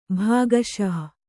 ♪ bhāgaśah